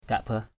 /ka:ʔ-bah/ (d.) đá thiêng Kaaba = Kaaba, sanctuaire de La Mecque. Kaaba, sanctuary of Mecca. batuw kakbah bt~| kKbH đá đen La Mecque = pierre noire de La Mecque.